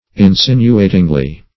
Insinuatingly \In*sin"u*a`ting*ly\, adv.